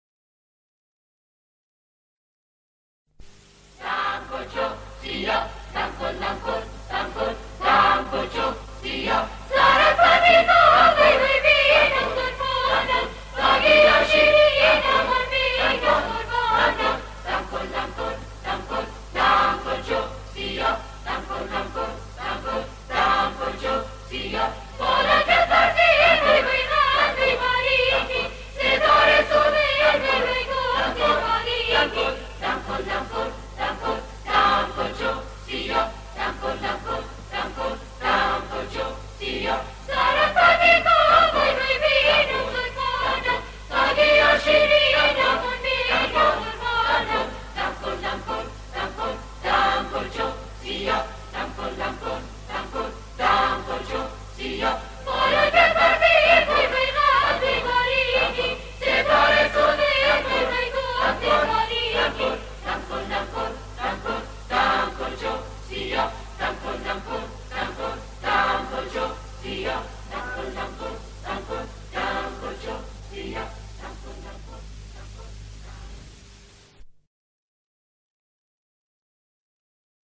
همخوانی شعری قدیمی
در این اثر، اجرای کرال در تمام قطعه انجام شده است.